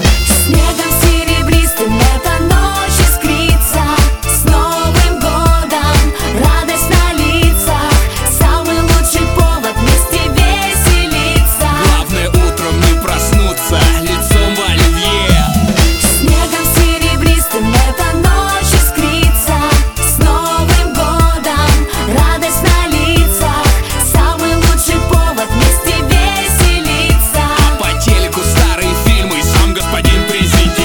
• Качество: 320, Stereo
позитивные
веселые
попса
добрые